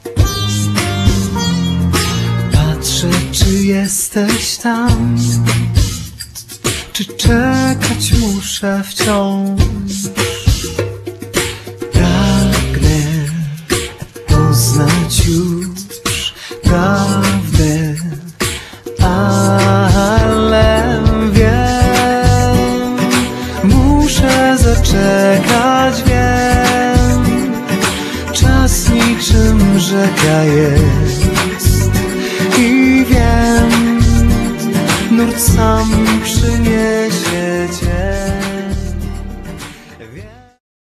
śpiew alikwotowy, sarangi